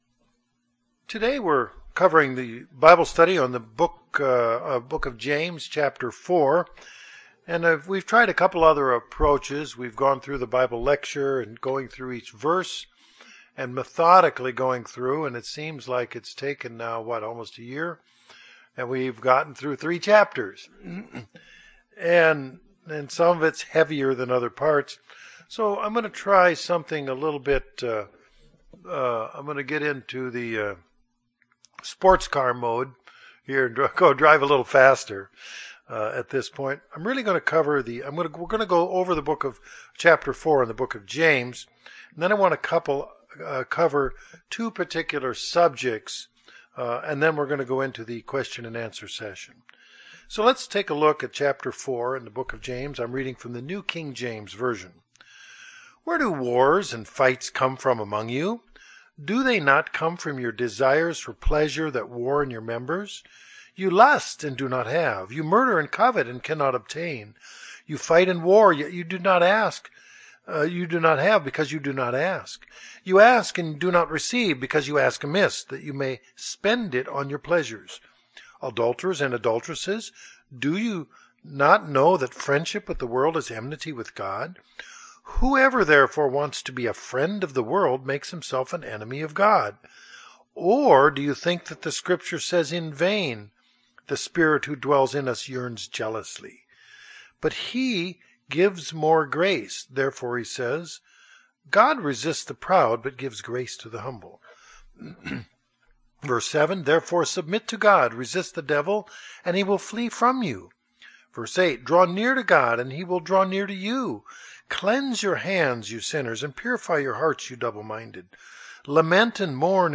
We continue our study of the Book of James with a survey and group discussion of chapter 4.